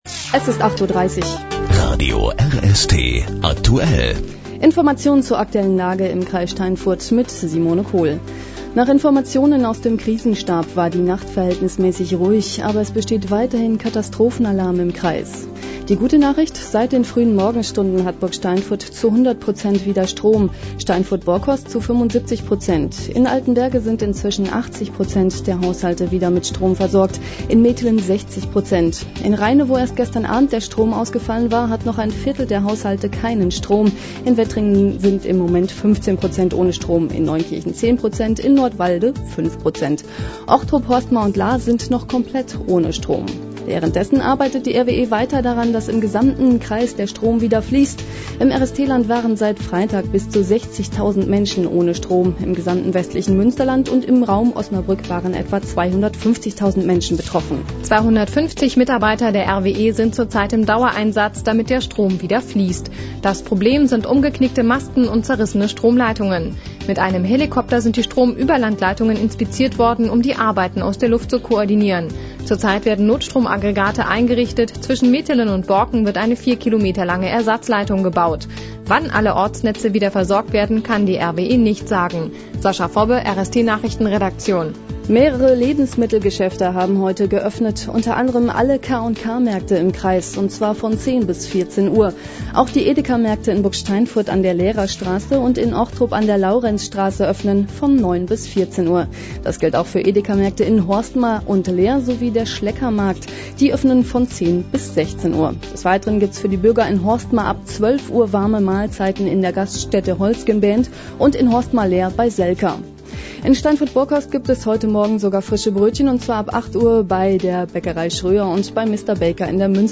Mitschnitt einer Nachrichtensendung von Radio RST: MP3-Datei öffnen.